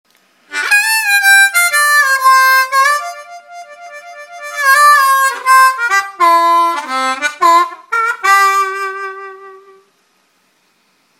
Трель на губной гармошке
Вот пример тихой трели в контексте более громких фраз, сыгранных на губной гармошке: